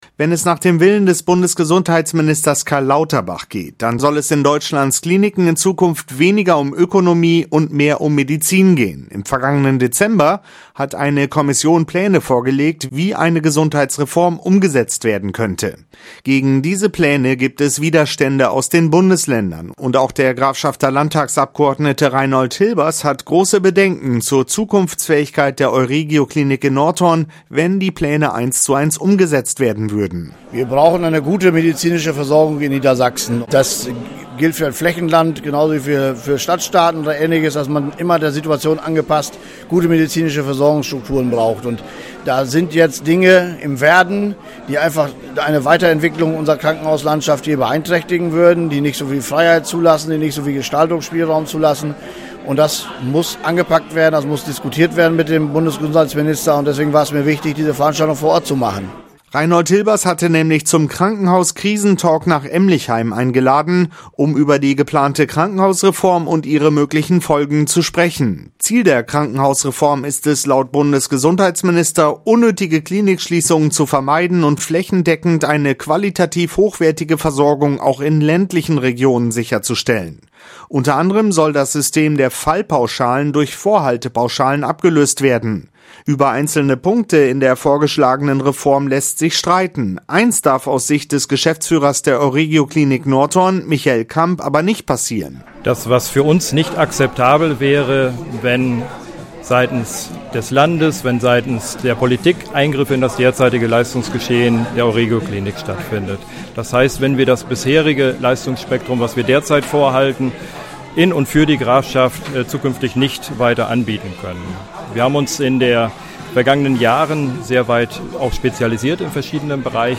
In der vergangenen Woche hat der Grafschafter Landtagsabgeordnete Reinhold Hilbers zu einem „Krankenhauskrisen-Talk“ nach Emlichheim eingeladen. Mit Experten hat er über die geplante Krankenhausreform und ihre Folgen gesprochen, wenn sie “eins zu eins” umgesetzt werden würde.